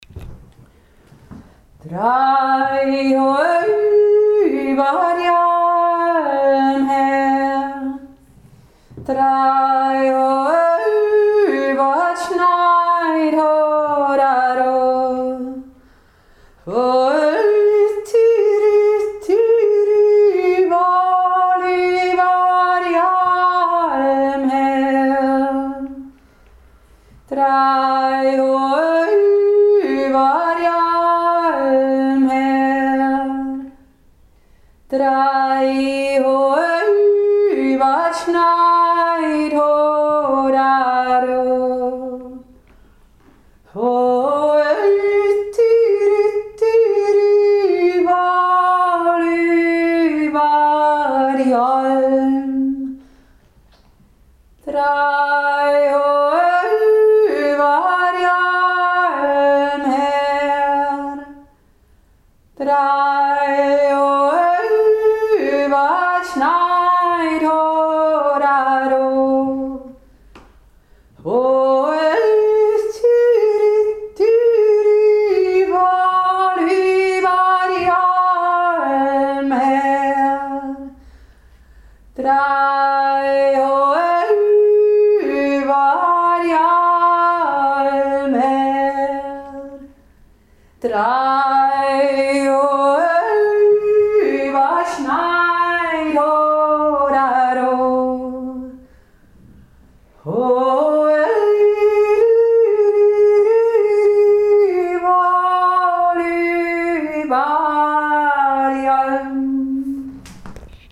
Goldegg jodelt März 2024
1. Stimme